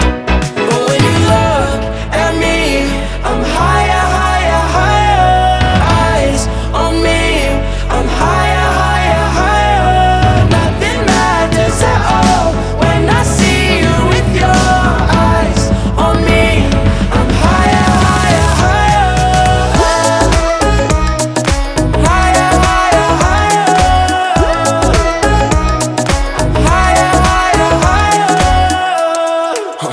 • Electronic